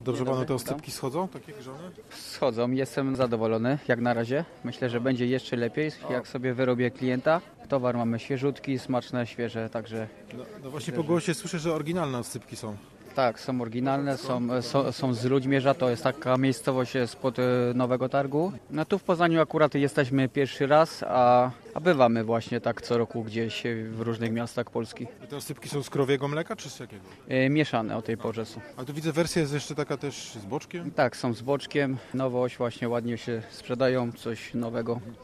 jnuw8nh00t20ixf_rozmawial-ze-sprzedawca-oscypkow.mp3